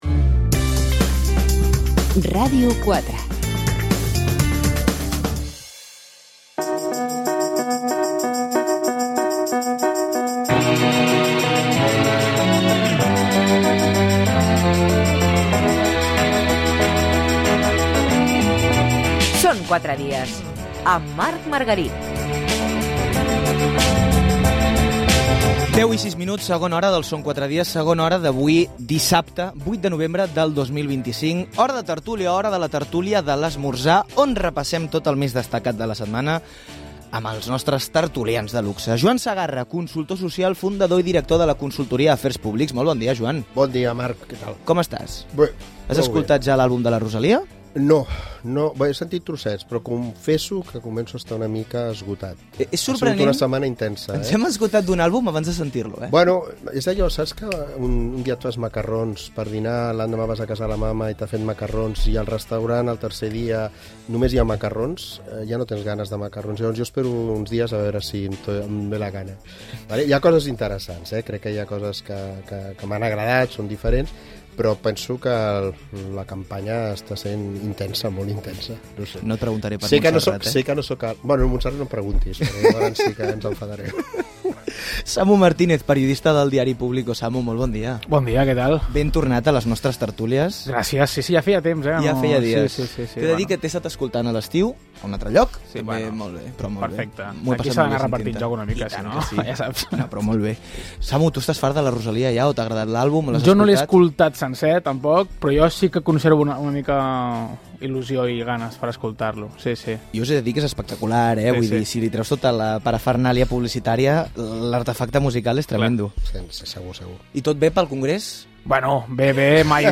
Tertúlia al Són 4 dies de Ràdio 4